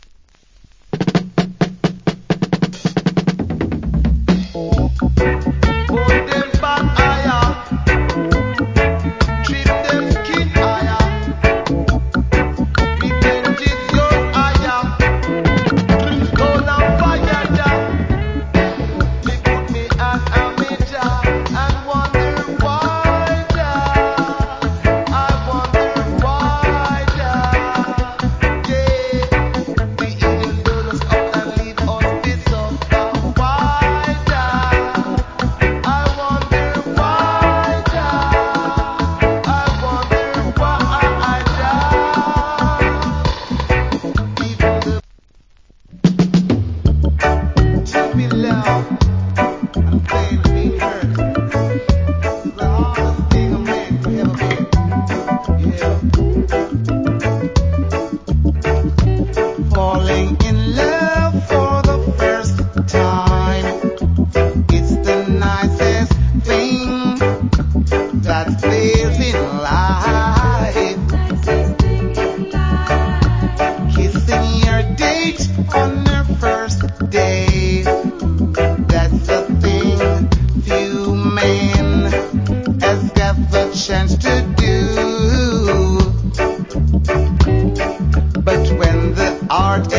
Wicked Roots Rock Vocal + Dub.